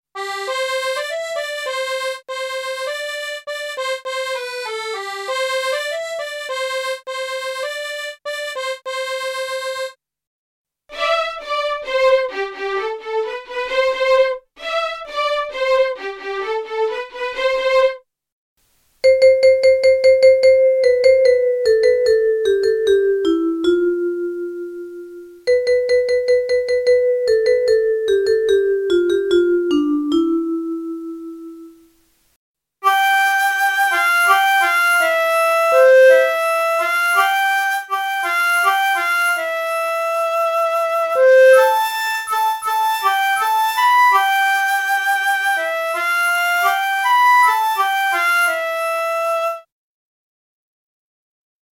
Ήρθε η ώρα να ακούσουμε και τον τέταρτο μουσικό να παίζει το χαρούμενο γαμήλιο βαλς.